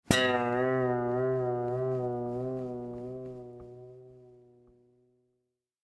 Звуки пружины
На этой странице собраны разнообразные звуки пружин: от резких щелчков до плавного скрипа.
Реальный звук пружины